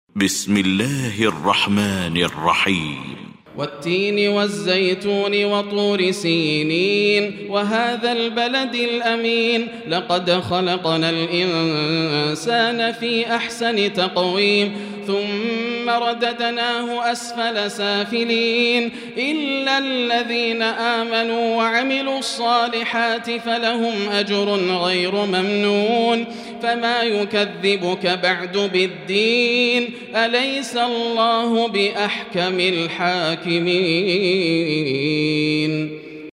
المكان: المسجد الحرام الشيخ: فضيلة الشيخ ياسر الدوسري فضيلة الشيخ ياسر الدوسري التين